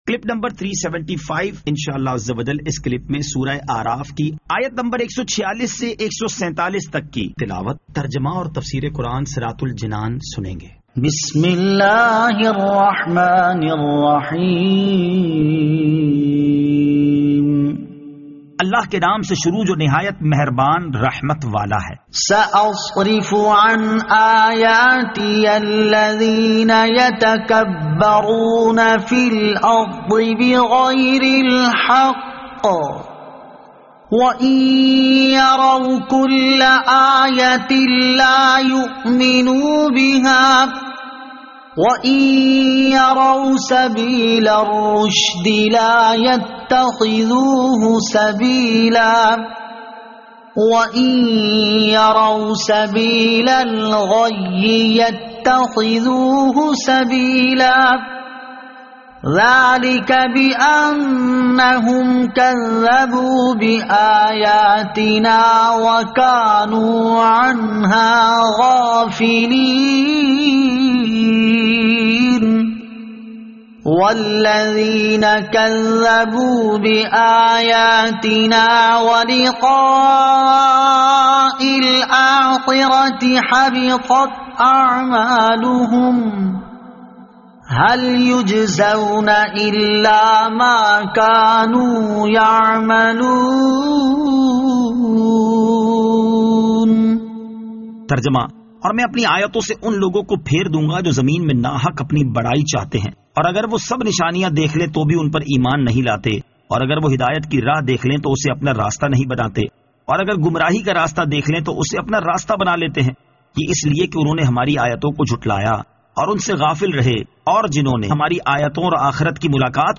Surah Al-A'raf Ayat 146 To 147 Tilawat , Tarjama , Tafseer